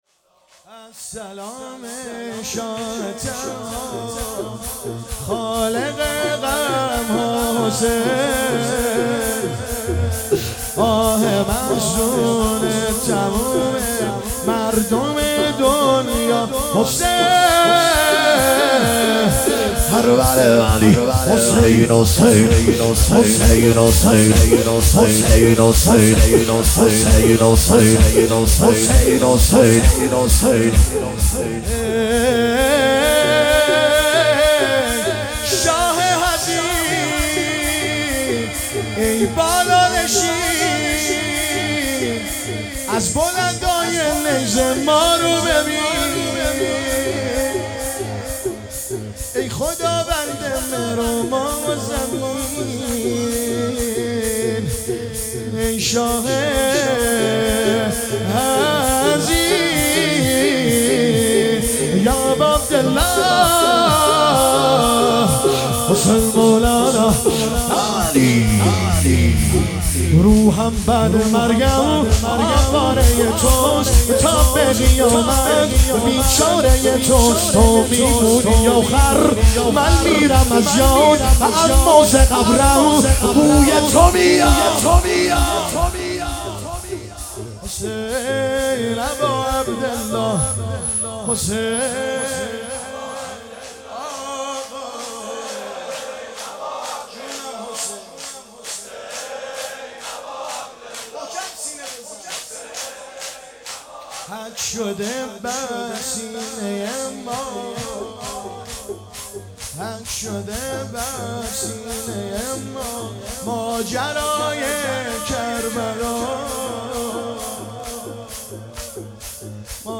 نوحه بسیار سوزناک و احساسی